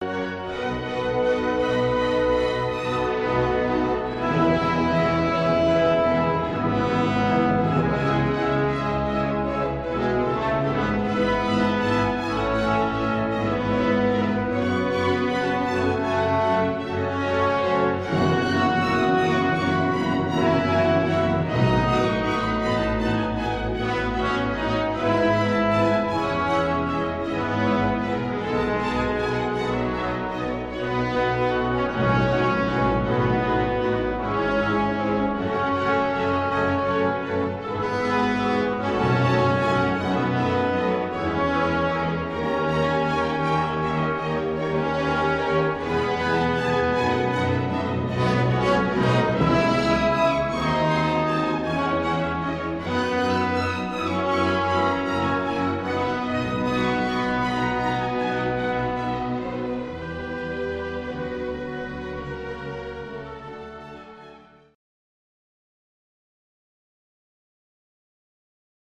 Ce qui caractérise la famille des cuivres, ce n'est pas le matériau, mais le fait qu'ils partagent le même type d'embouchure.
trombone, Wagner